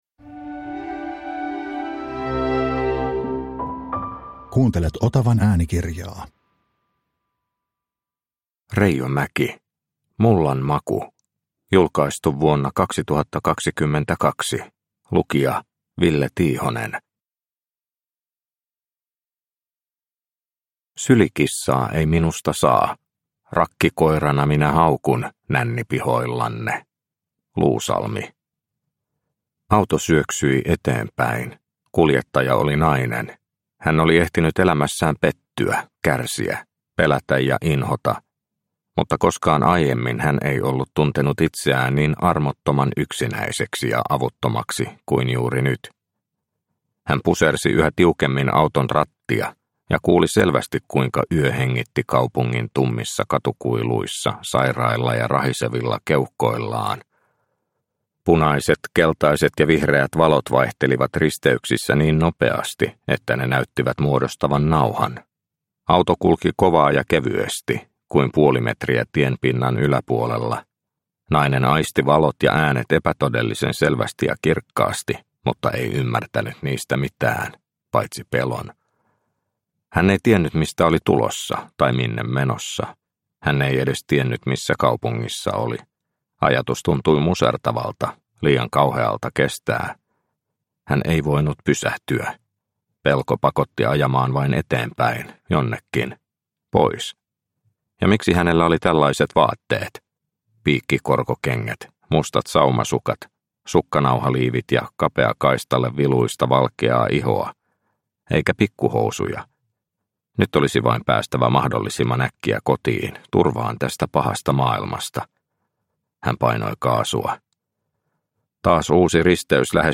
Mullan maku – Ljudbok – Laddas ner